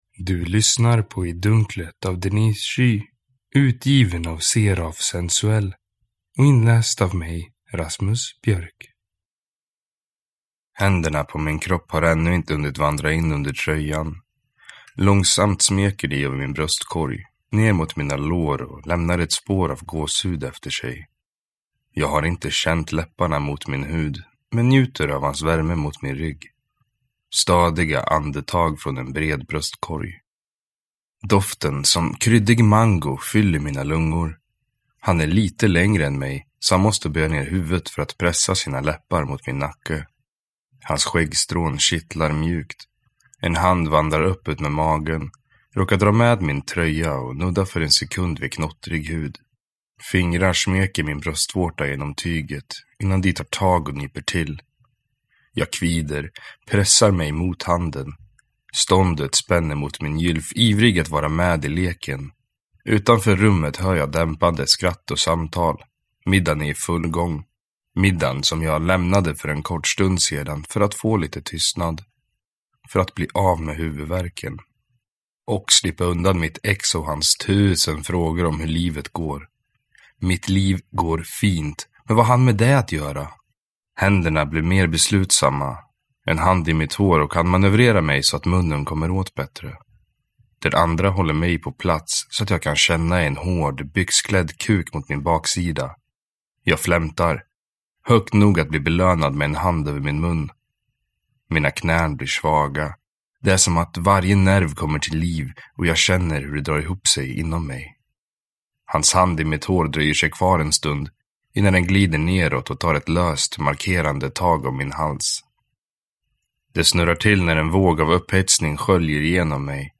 I dunklet (ljudbok) av Denise Ky